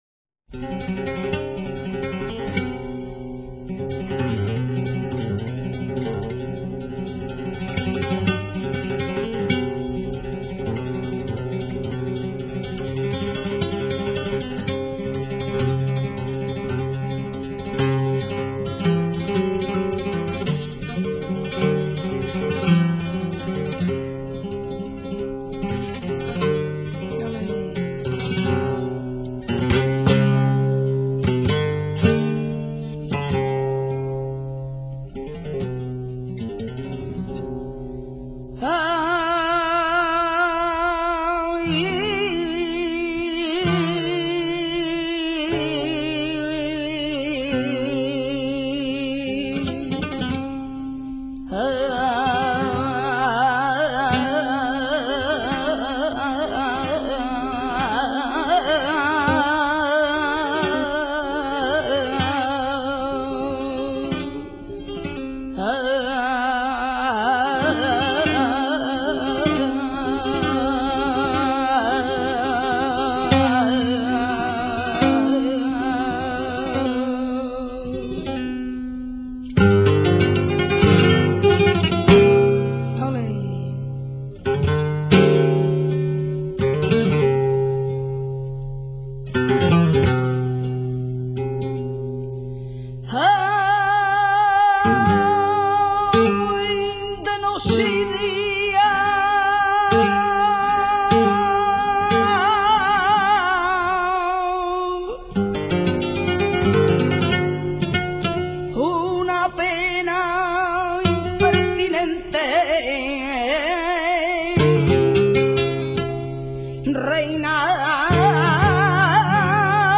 Sonidos y Palos del Flamenco
cartagenera.mp3